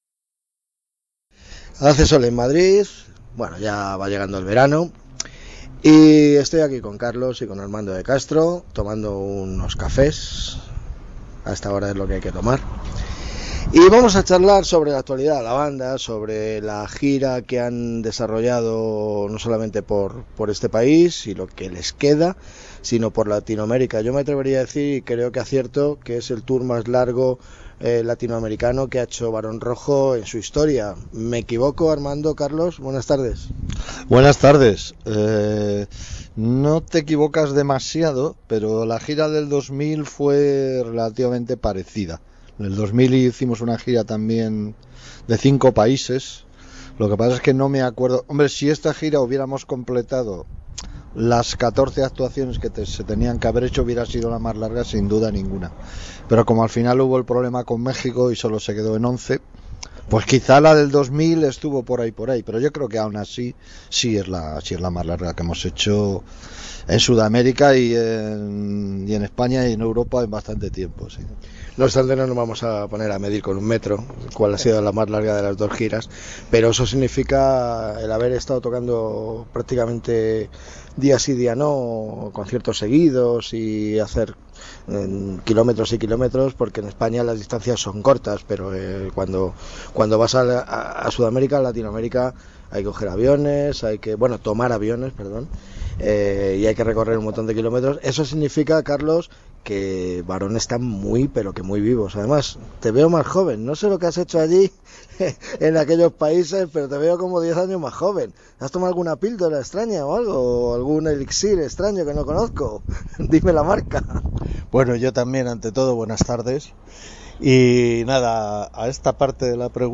El tour ha sido un gran éxito en todos los aspectos; por ello esta tarde de martes quedé con Armando y Carlos de Castro en los locales de ensayo "La Música" para hablar sobre ello. Ambos recordaron detalles y anécdotas de la gira que les llevó a Colombia (1 concierto), Ecuador (3 conciertos), Bolivia (4 conciertos), Chile (1 concierto), Argentina (1 concierto), y Uruguay (1 concierto).